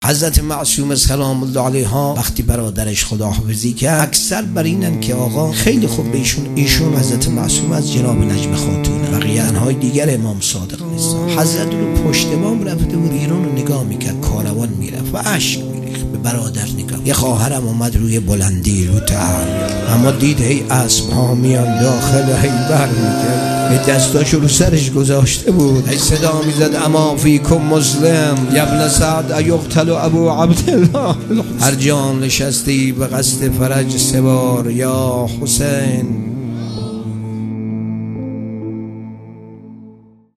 روضه
هیئت ام ابیها قم | شهادت حضرت معصومه سلام الله علیها 1401